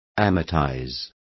Complete with pronunciation of the translation of amortizes.